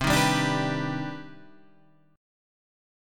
CmM9 chord {8 6 5 7 8 7} chord